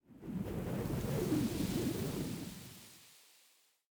housewind03.ogg